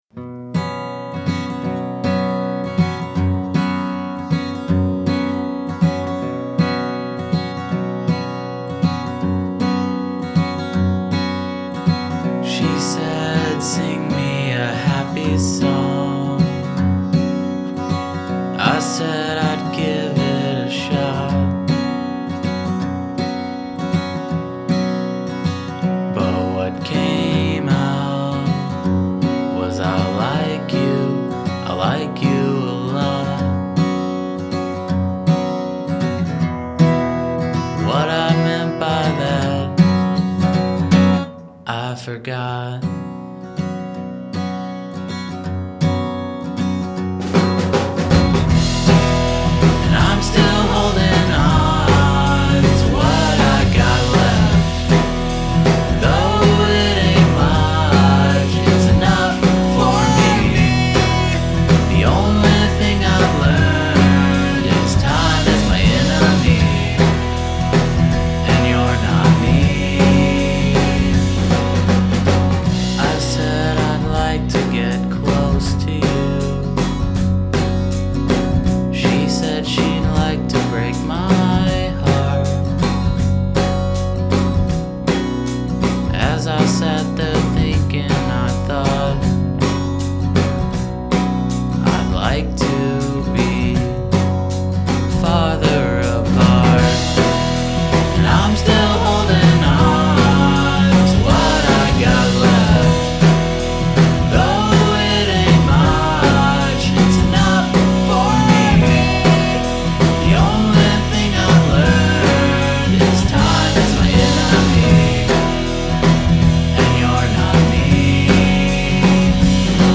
I recorded these songs in my home studio in 2009.